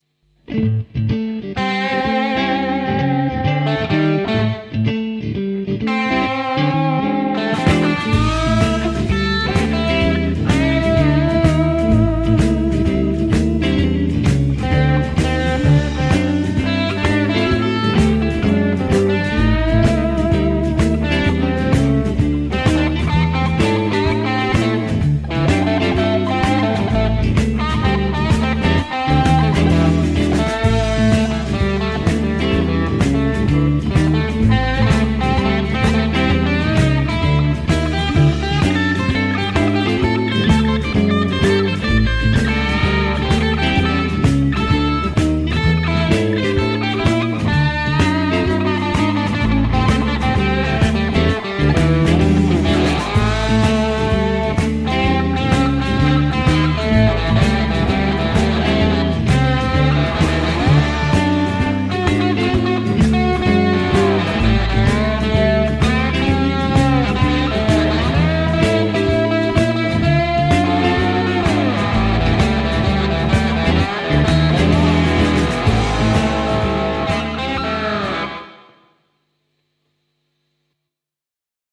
- Laadi annetun taustan päälle solistinen osuus valitsemallasi instrumentilla
jotenkin elokuvallinen tunnelma, ehkä tuo soundi ja rytmitys. 2p
Ilmeisesti slidellä ainakin osittain vedetty, toimivia rauhallisen viipyileviä kuvioita ja hyvä vire. Rytmisesti aika vapaa meininki, pääosin kuitenkin tyylilajin mukaisissa puitteissa.